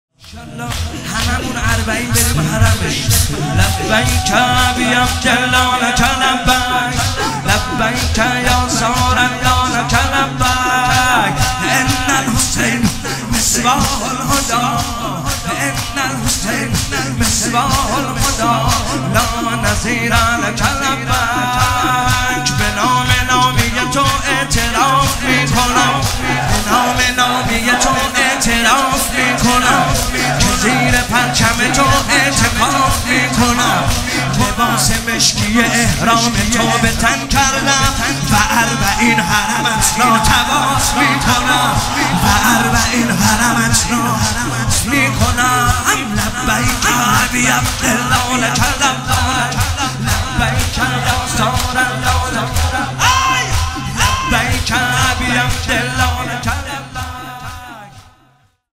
مداحی
محرم 1398